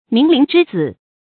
螟蛉之子 míng líng zhī zǐ 成语解释 螟蛉：绿色小虫，寄生蜂蜾赢常捕捉螟蛉，产卵在它们身体里，卵孵化后就拿螟蛉作食物。